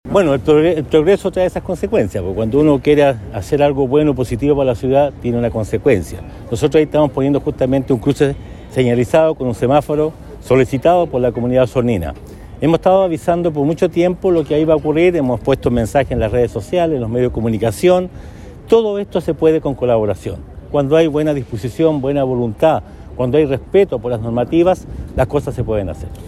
El Alcalde Emeterio Carrillo señaló que este tipo de obras generan un impacto directo en la comunidad y pueden causar molestias durante el periodo en que se ejecuten pero, a la larga, son iniciativas que contribuyen al crecimiento y desarrollo de la ciudad.